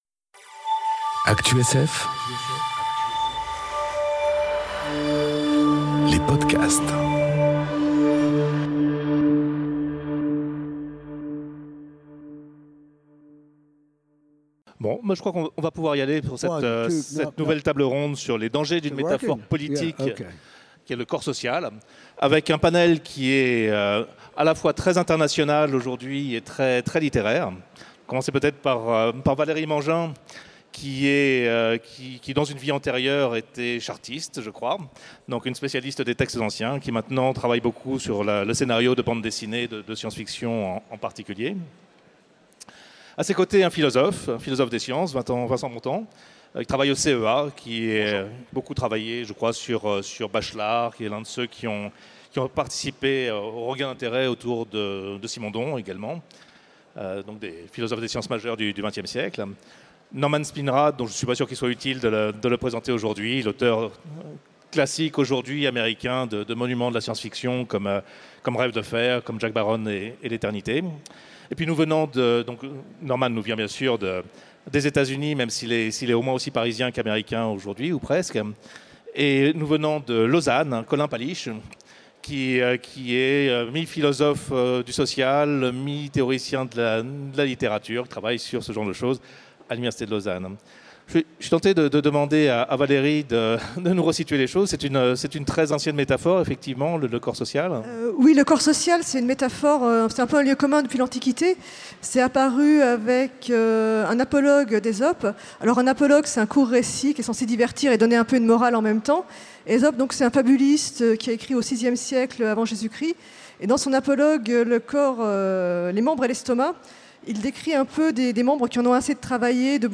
Conférence Les dangers d’une métaphore politique : le corps social enregistrée aux Utopiales 2018